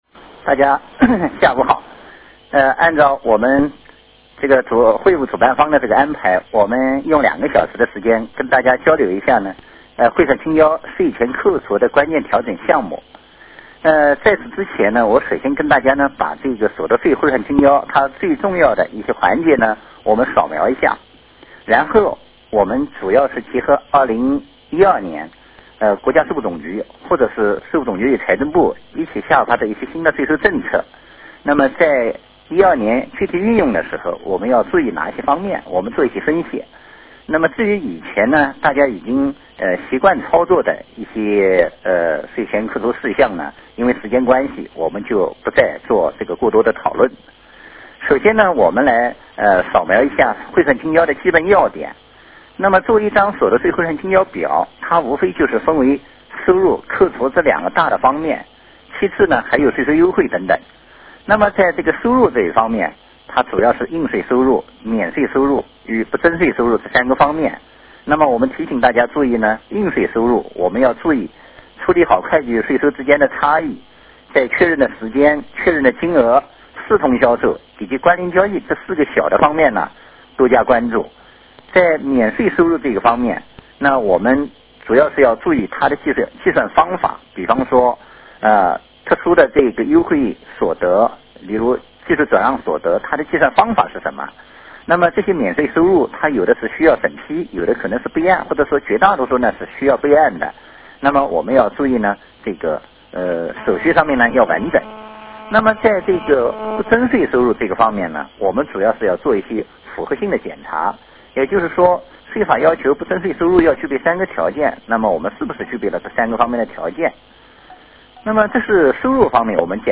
电话会议
互动问答